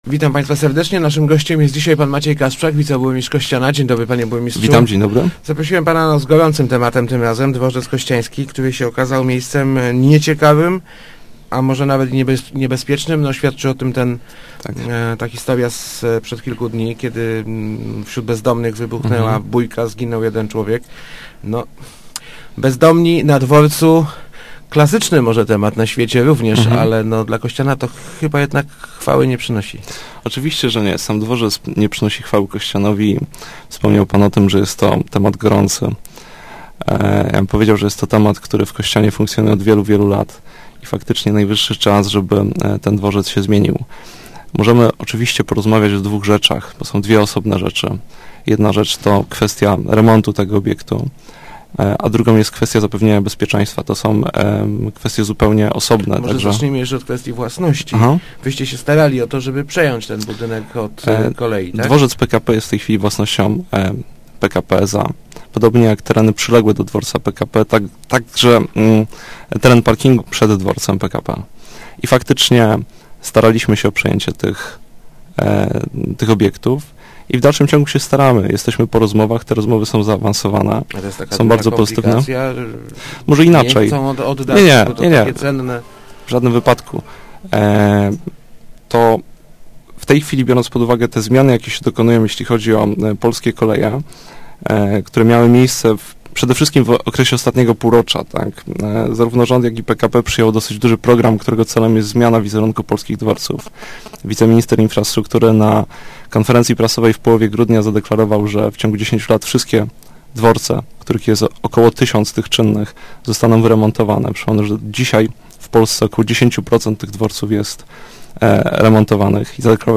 Złożyliśmy już prośbę do PKP, żeby dworzec był na noc zamykany – mówił w Rozmowach Elki wiceburmistrz Kościana Maciej Kasprzak.